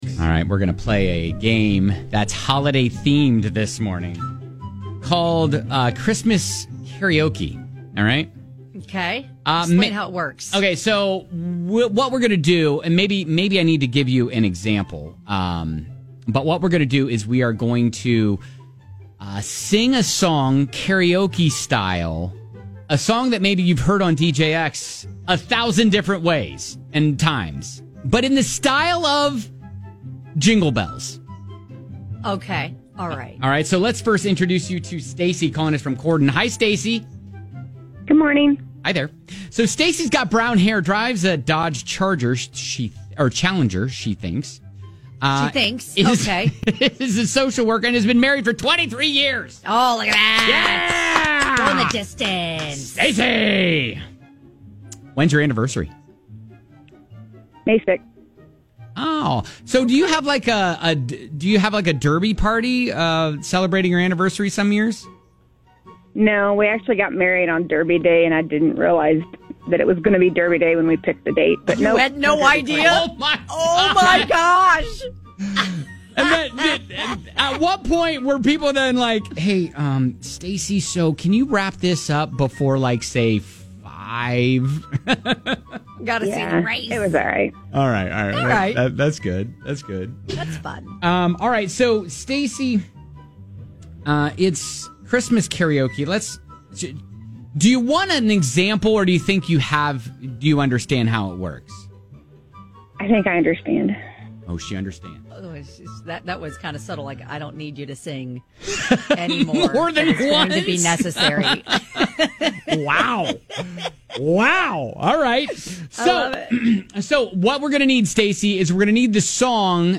Guess the pop song we're performing 'in the style of' a popular Christmas tune!